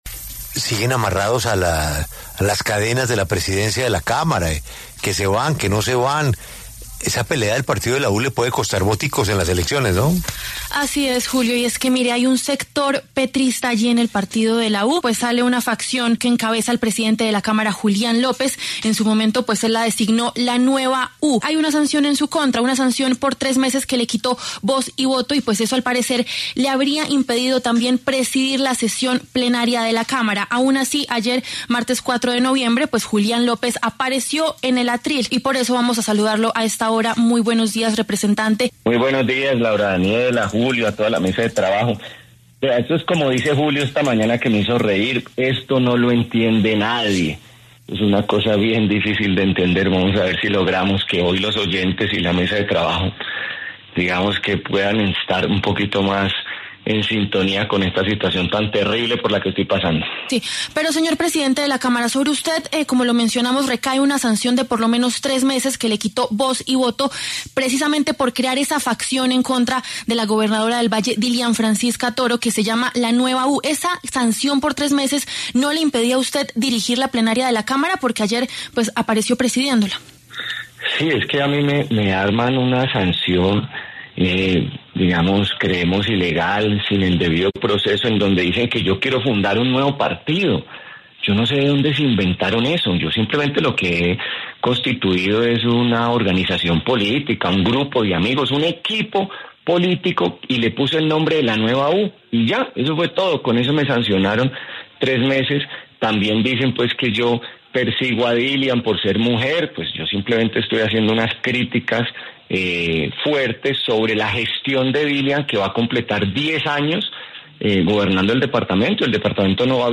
Julián López, presidente de la Cámara y congresista del Partido de La U, pasó por los micrófonos de La W para hablar sobre su choque con Dilian Francisca Toro.